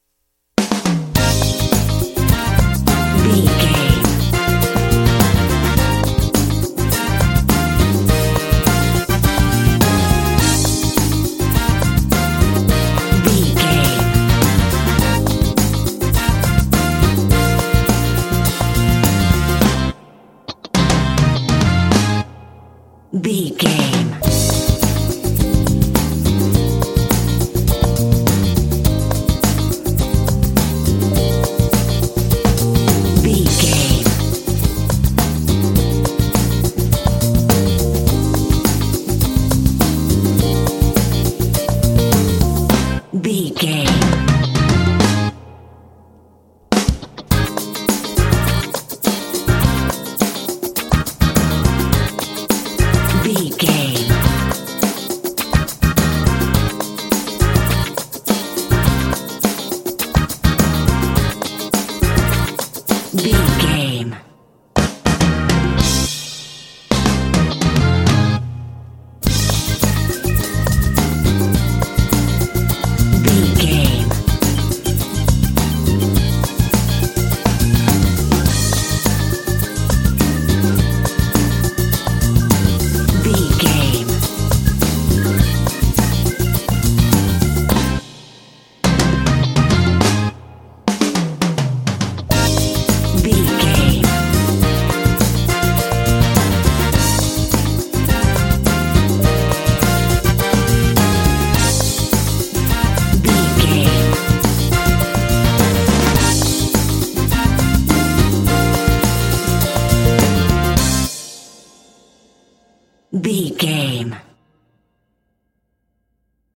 Aeolian/Minor
groovy
driving
energetic
electric organ
electric piano
drums
bass guitar
electric guitar